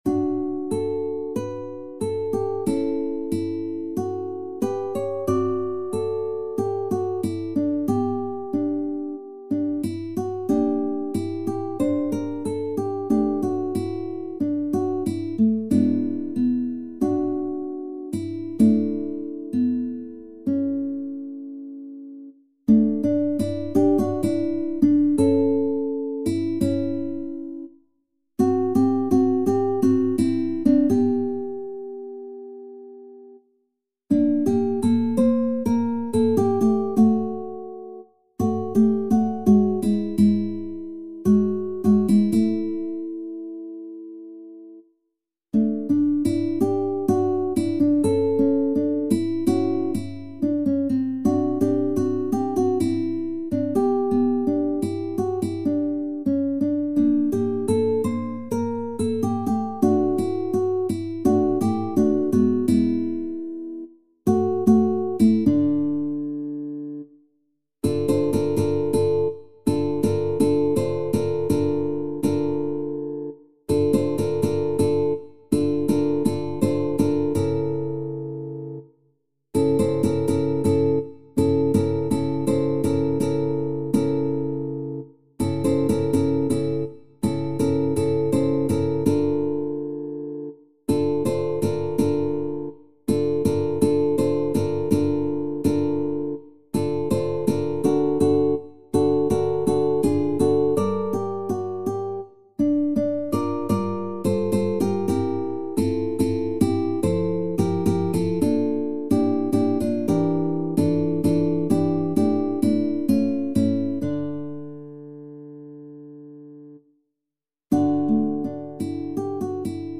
SSAA | SSAB